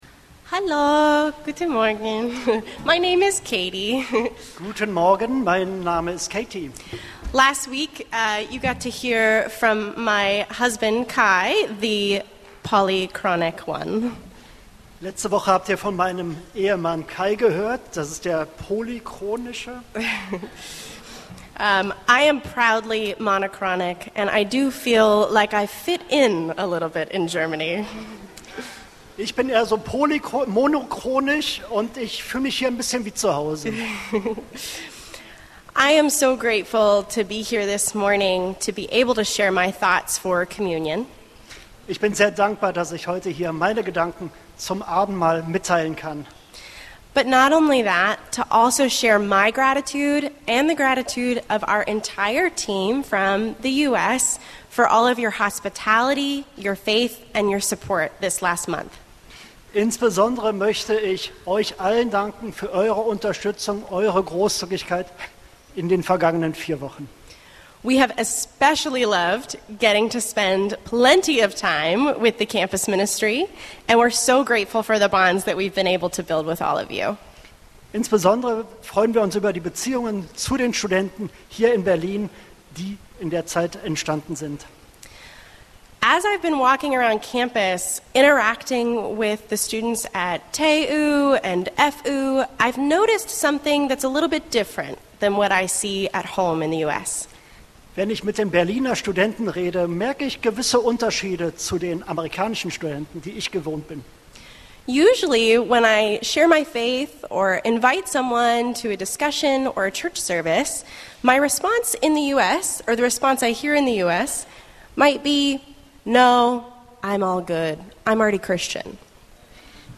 E-Mail Details Predigtserie: Abendmahl Datum